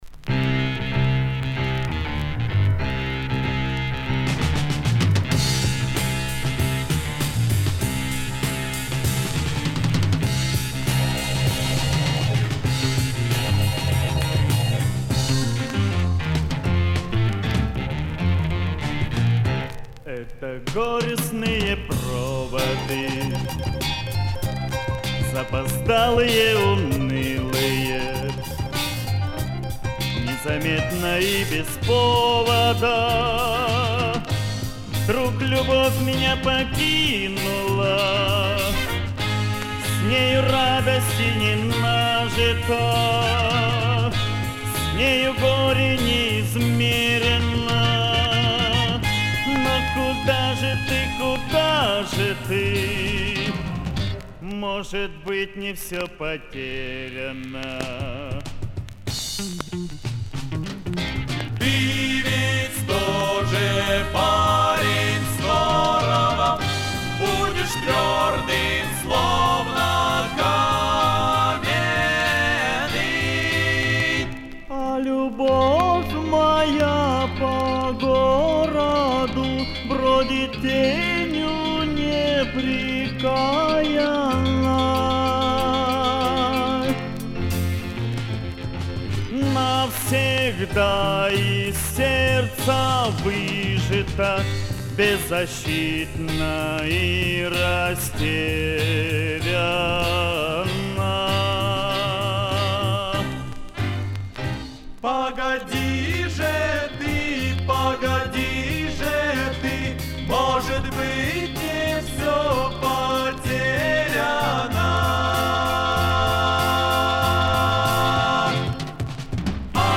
Винил